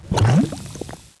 c_slime_atk3.wav